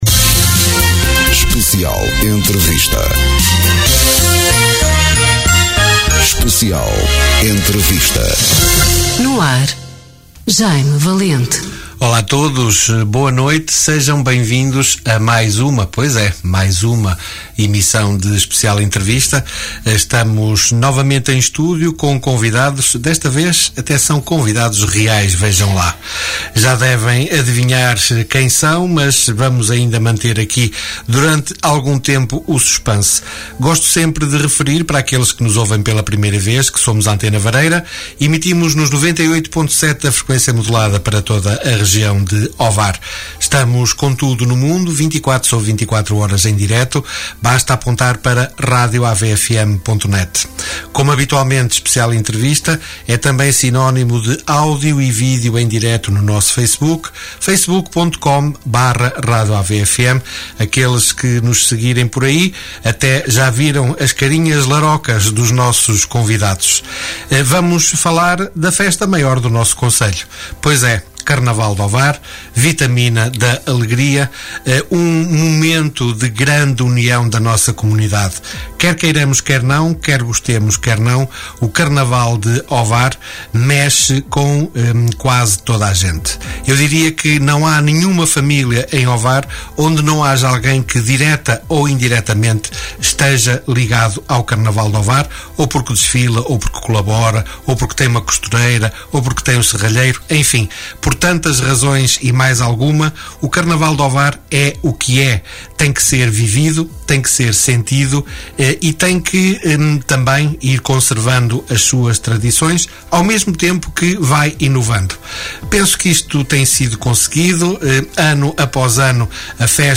Especial Entrevista
Direitos reservados Especial Entrevista Conversas olhos nos olhos em direto Mais informações